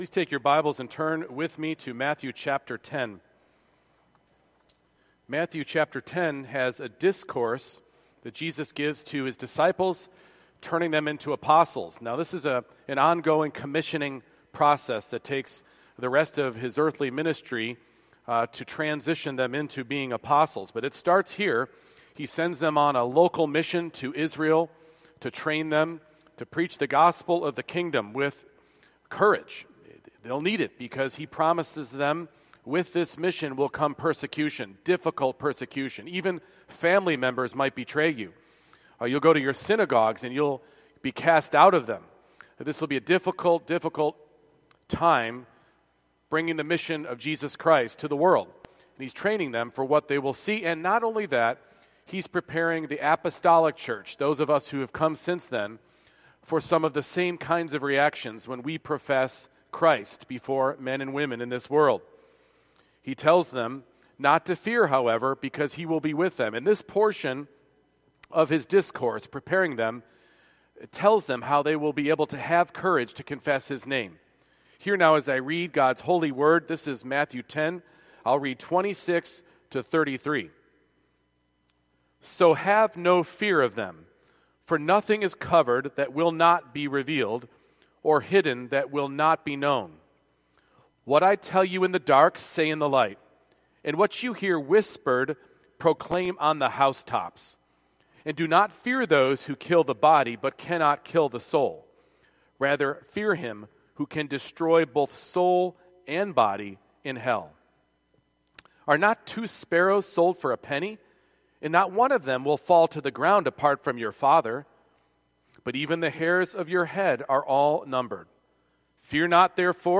Courage for Confessing Christ - Redeemer Presbyterian Church, Overland Park, KS